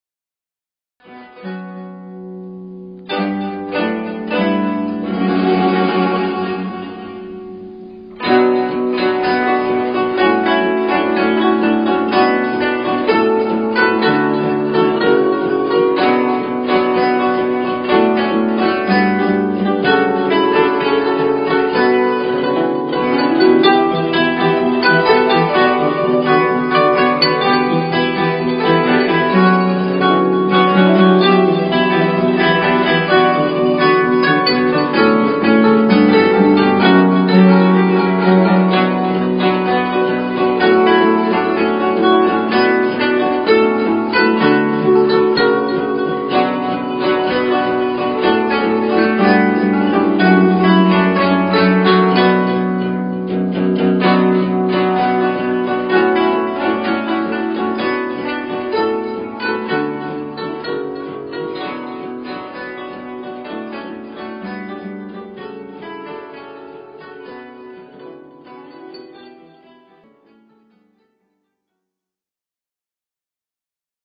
箏1
箏2
箏3
十七絃